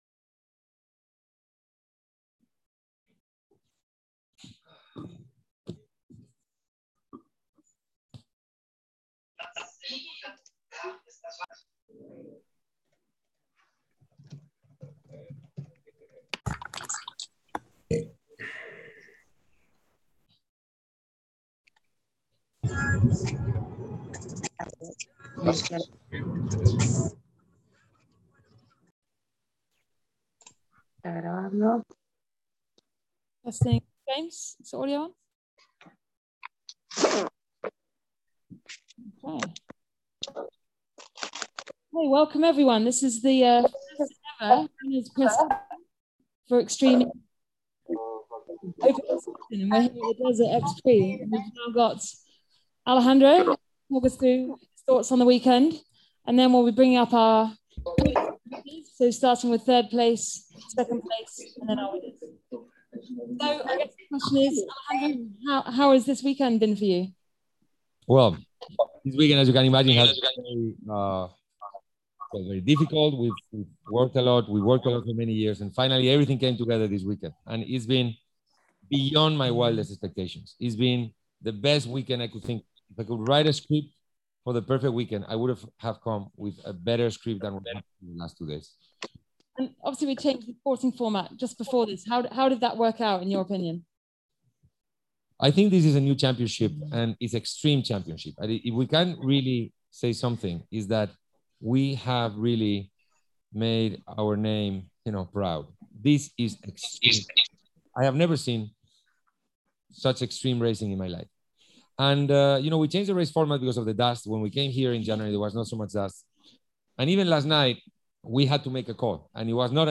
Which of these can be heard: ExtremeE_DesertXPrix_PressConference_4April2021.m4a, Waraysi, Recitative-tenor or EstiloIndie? ExtremeE_DesertXPrix_PressConference_4April2021.m4a